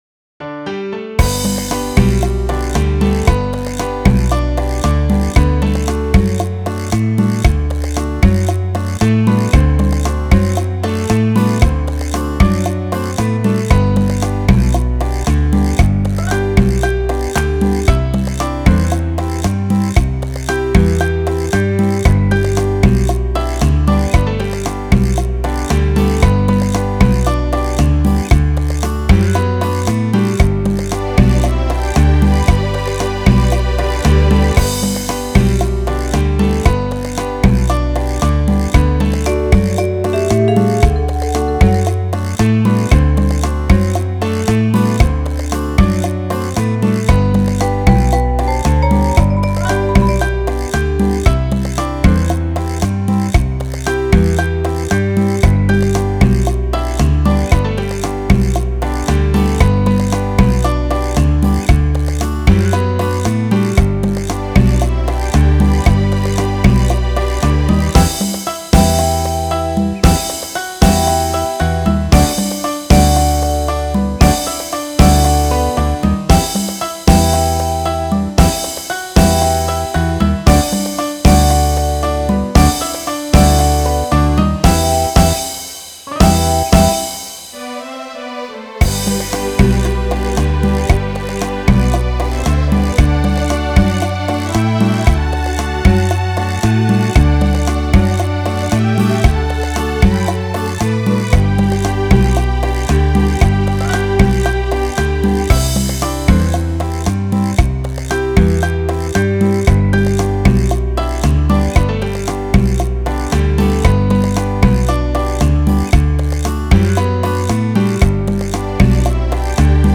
Beach music has to include this song.